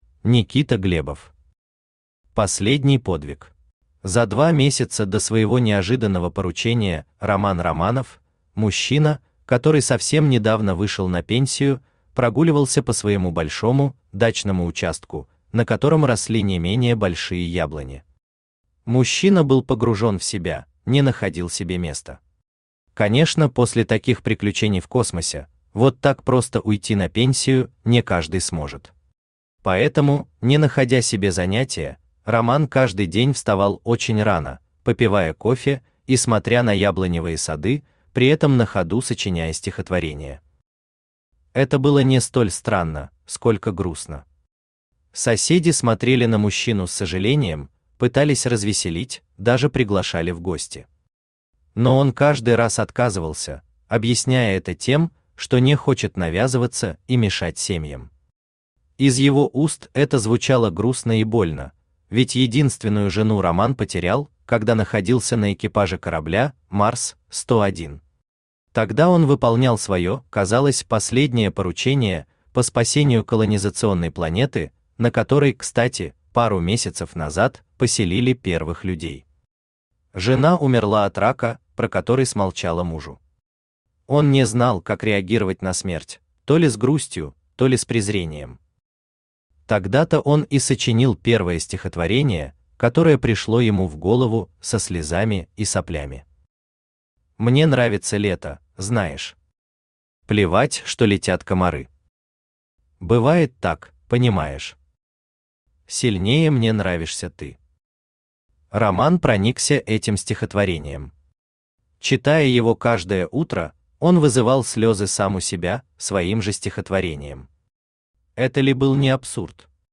Aудиокнига Последний подвиг Автор Никита Александрович Глебов Читает аудиокнигу Авточтец ЛитРес.